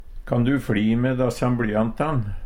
dassan - Numedalsmål (en-US)